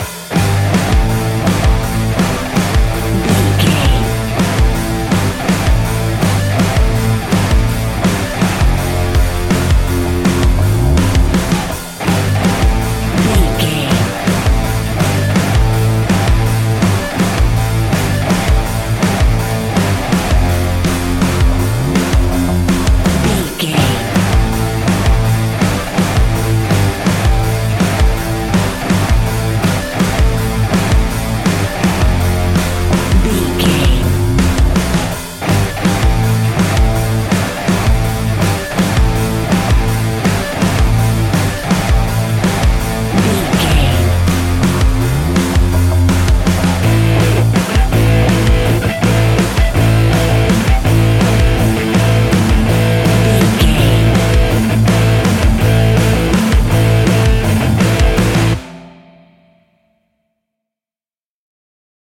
Ionian/Major
hard rock
guitars
heavy metal
instrumentals